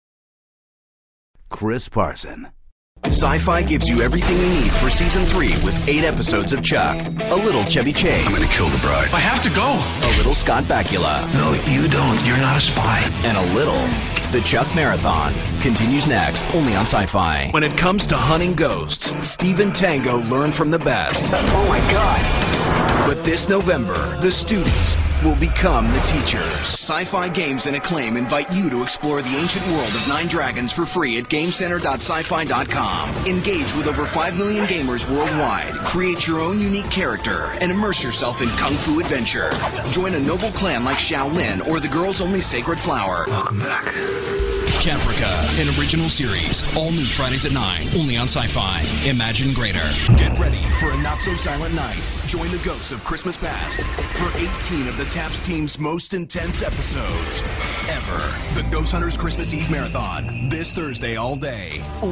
Trailers